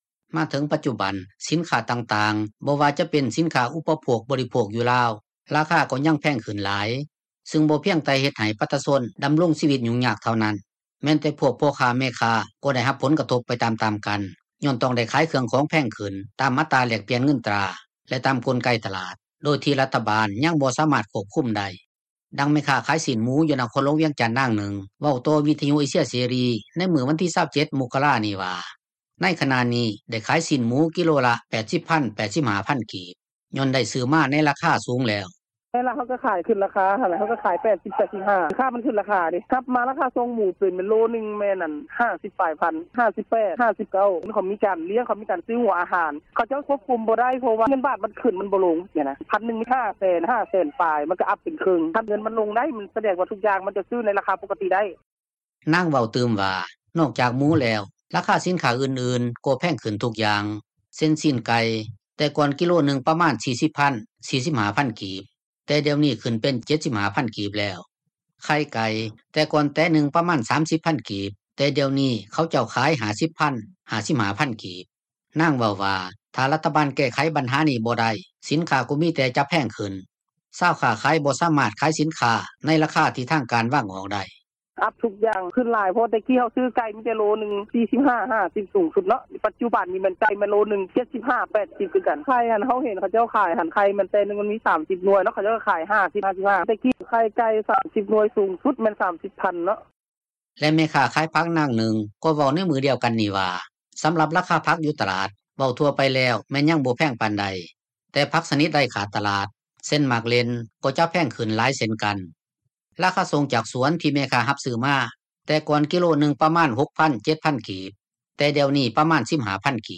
ດັ່ງແມ່ຄ້າຂາຍເຄື່ອງກໍ່ສ້າງ ນາງນຶ່ງເວົ້າວ່າ:
ດັ່ງເຈົ້າໜ້າທີ່ ກະຊວງອຸດສາຫະກັມ ແລະການຄ້າ ຜູ້ບໍ່ປະສົງອອກຊື່ ແລະຕໍາແໜ່ງ ທ່ານນຶ່ງ ເວົ້າວ່າ: